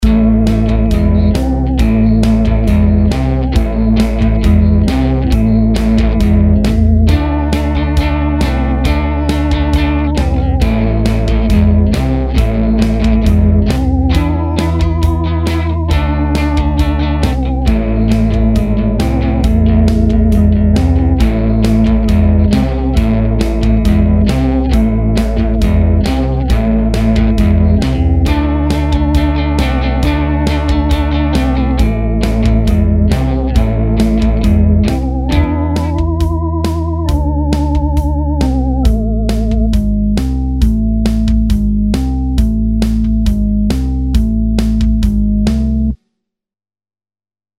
Boah, c'est que des bouts de trucs, une simple base de travail, avec une batterie midi pourrie (pas bossé dessus)... rien de défintif... mais puisque tu demandes :mrg:
En fait j'aime beaucoup l'ambiance seventies qui se dégage de ces morceaux.
pour moi aussi grand moment de rock'n'roll